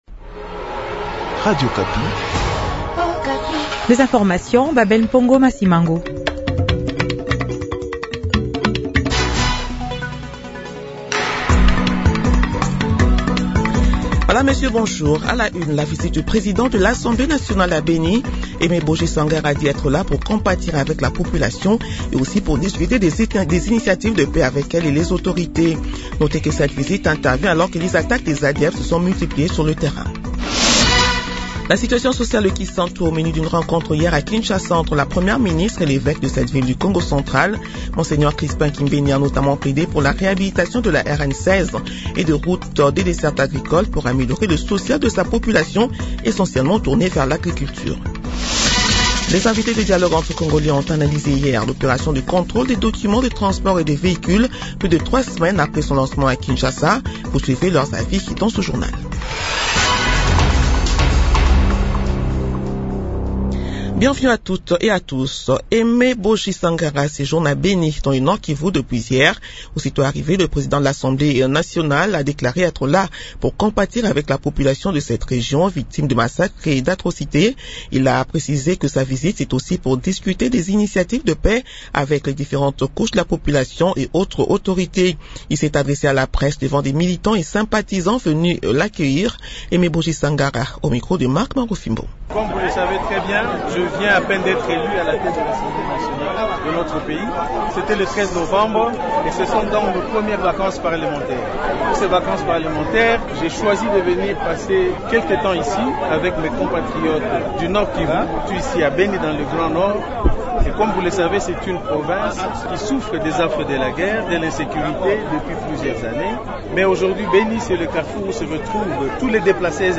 Journal matin 8 heures